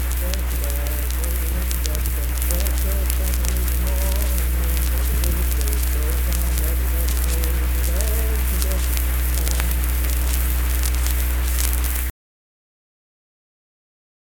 Unaccompanied vocal music
Verse-refrain 1(4). Performed in Kanawha Head, Upshur County, WV.
Voice (sung)